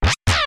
Sound effect from Super Mario RPG: Legend of the Seven Stars
Self-recorded using the debug menu
SMRPG_SFX_Yoshi.mp3